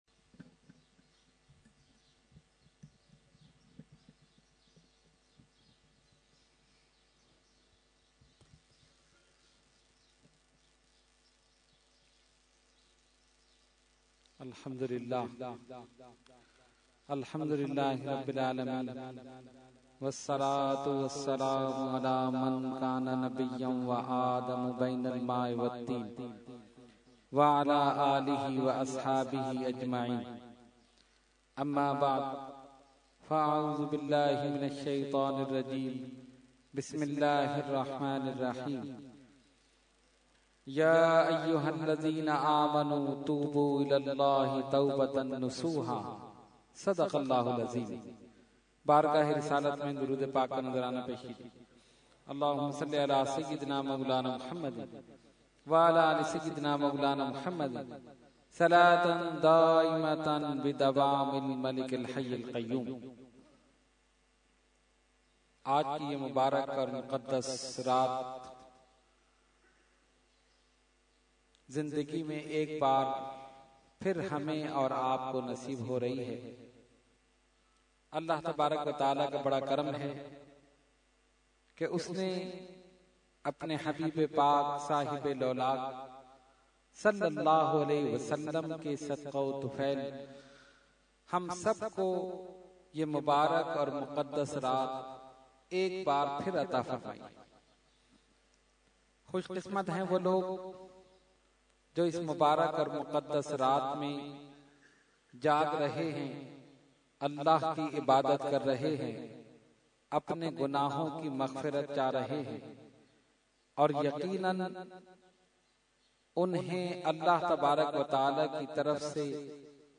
Category : Speech | Language : UrduEvent : Shab e Baraat 2014